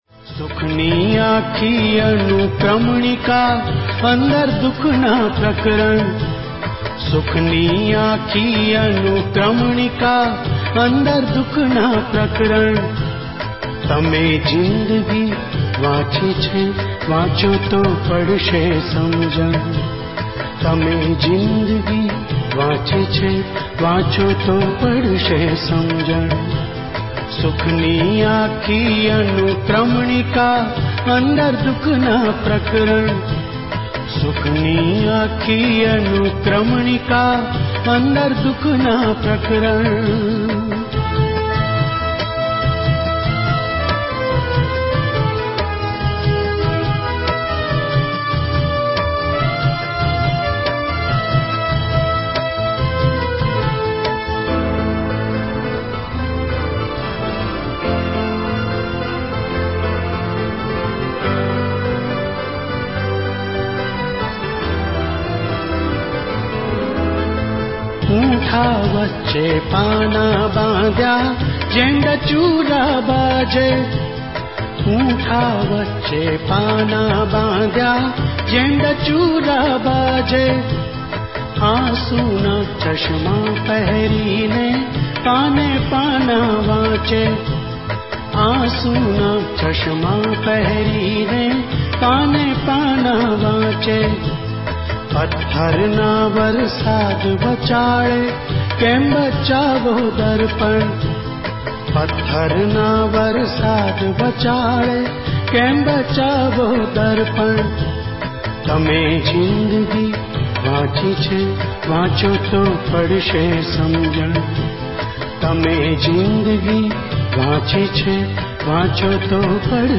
સુખની આખી અનુક્રમણિકા - Sukhani Akhi Anukramanika - Gujarati - લોક ગીત (Lok-Geet) - Gujarati World